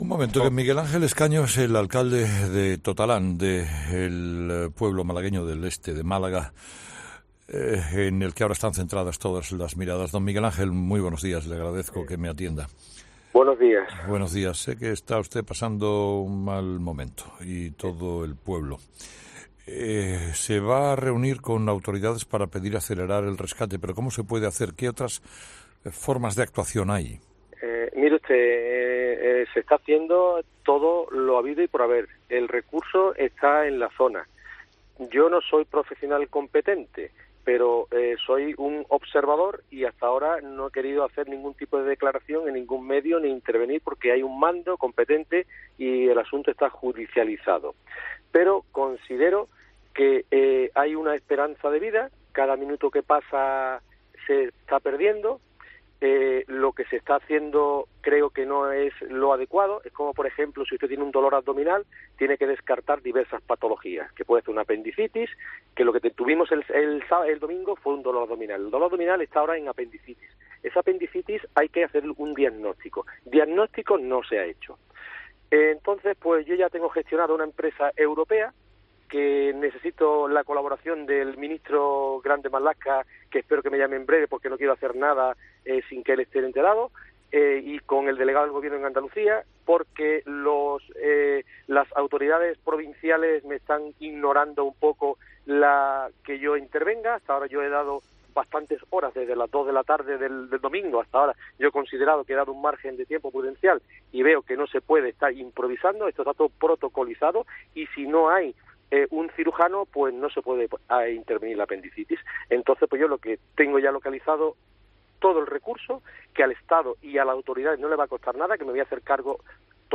Entrevista a Miguel Ángel Escaño
Entrevistado: "Miguel Ángel Escaño"